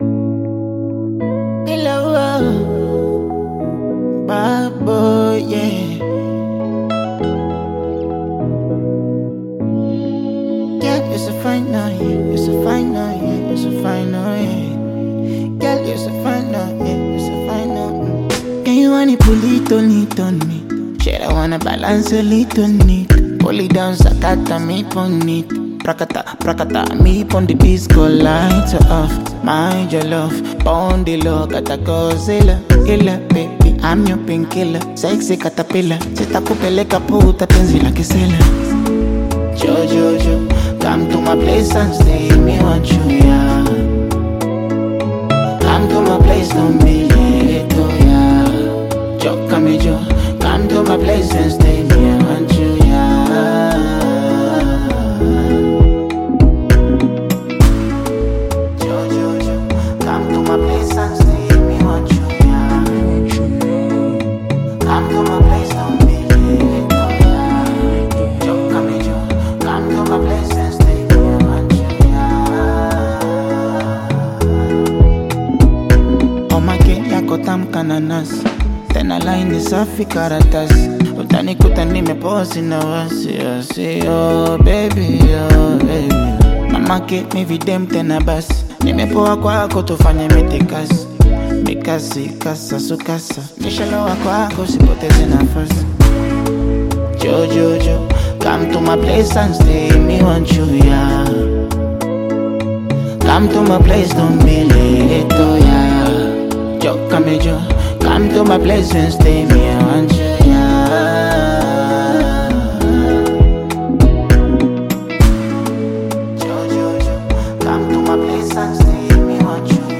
Bongo Flava artist
This catchy new song